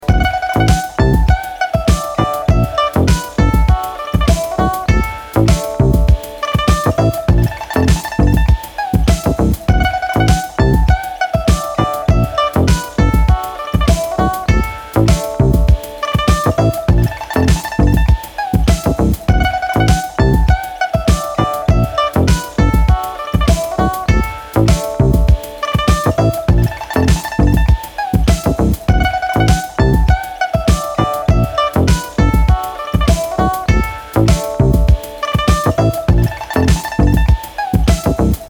high-quality MP3- 320 or WAV